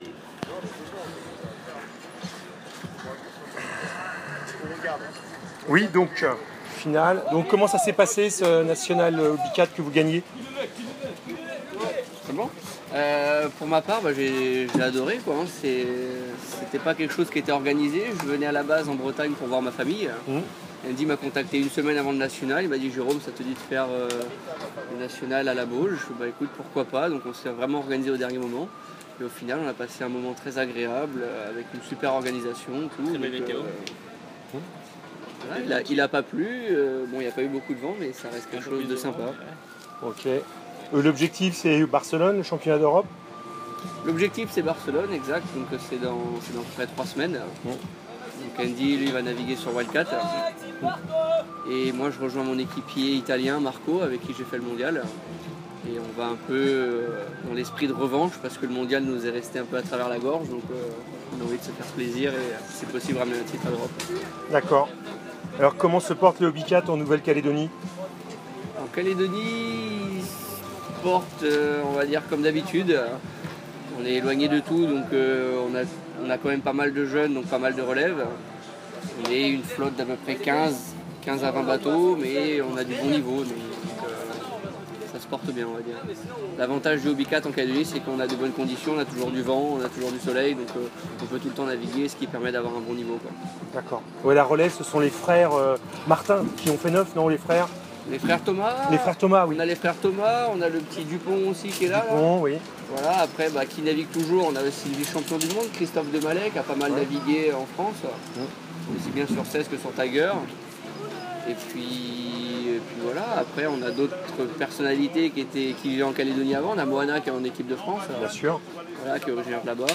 le son de l’entretien: